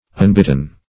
unbitten - definition of unbitten - synonyms, pronunciation, spelling from Free Dictionary